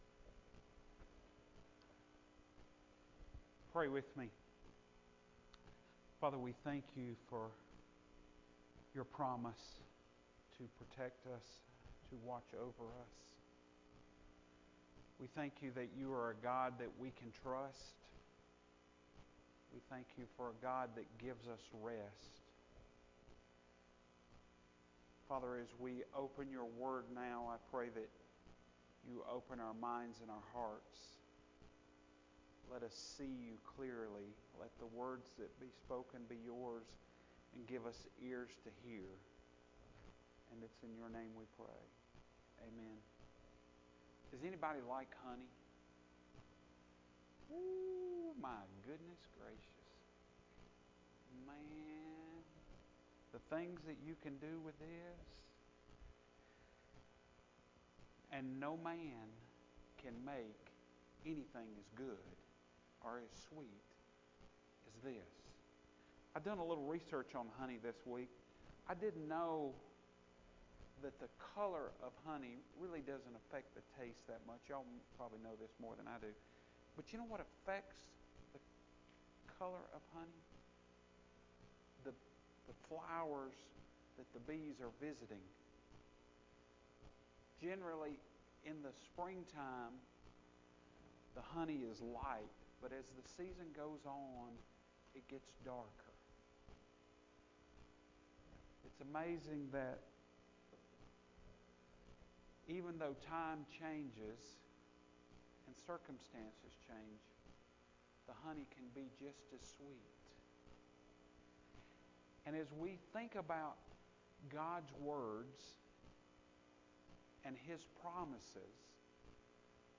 Sermon Only